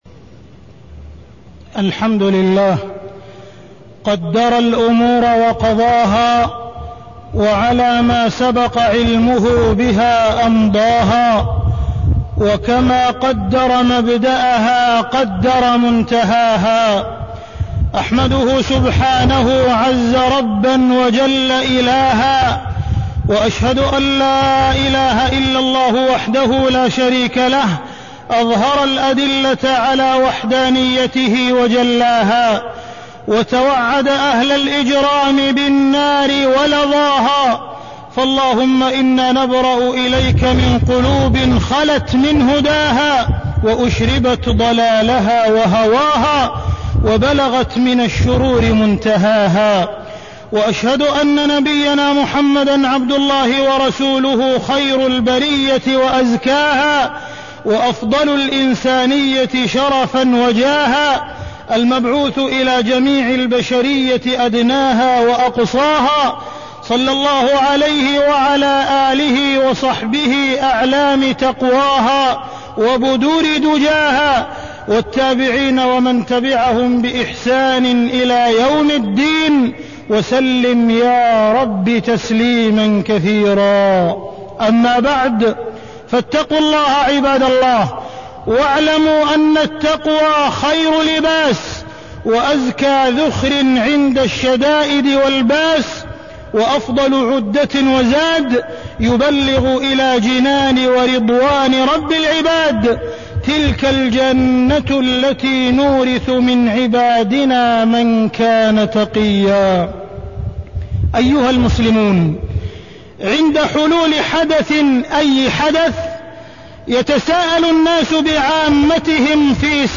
تاريخ النشر ٢٩ ربيع الأول ١٤٢٤ هـ المكان: المسجد الحرام الشيخ: معالي الشيخ أ.د. عبدالرحمن بن عبدالعزيز السديس معالي الشيخ أ.د. عبدالرحمن بن عبدالعزيز السديس الإفساد والإرهاب